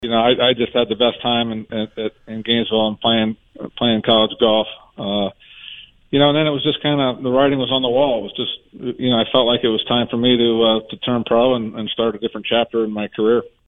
Interview on Sportscene